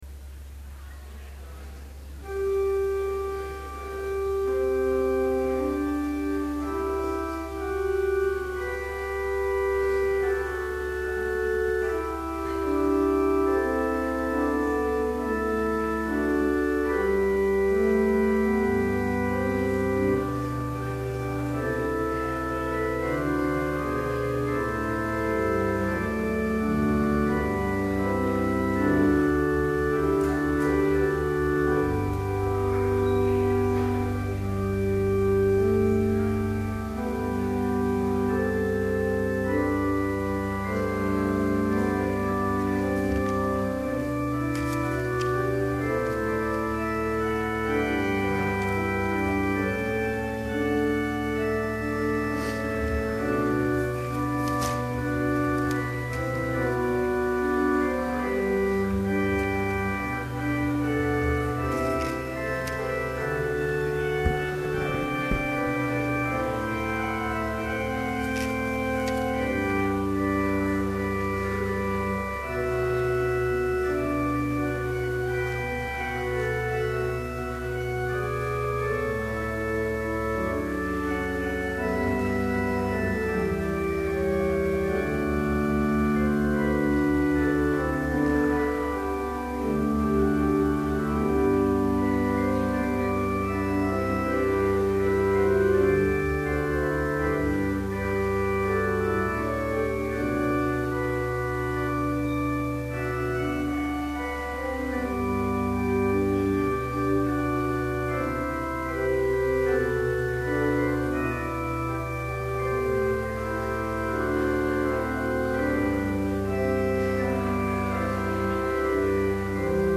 Complete service audio for Chapel - February 9, 2012